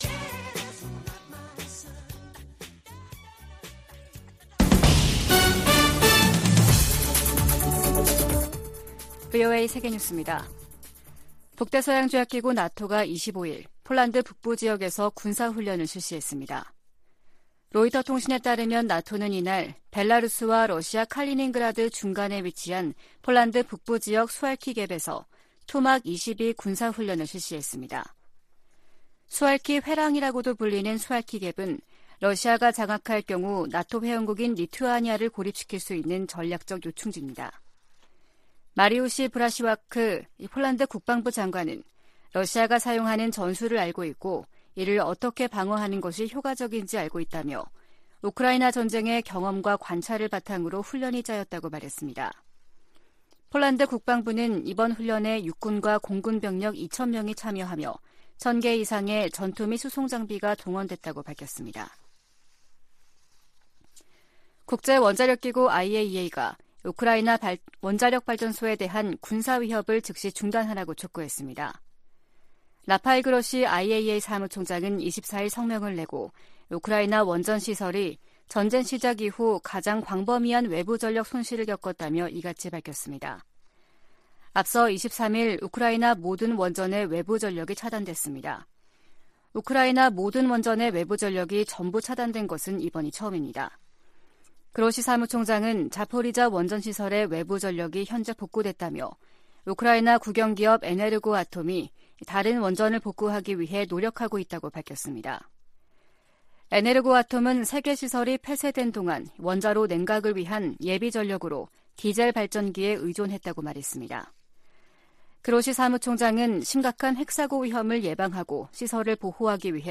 VOA 한국어 아침 뉴스 프로그램 '워싱턴 뉴스 광장' 2022년 11월 26일 방송입니다. 미국 의회에서 북한의 도발을 방조하는 ‘세컨더리 제재’등으로 중국에 책임을 물려야 한다는 요구가 거세지고 있습니다. 미국 고위 관리들이 최근 잇따라 북한 문제와 관련해 중국 역할론과 책임론을 거론하며 중국의 협력 의지를 시험하고 있다는 전문가 분석이 제기됐습니다.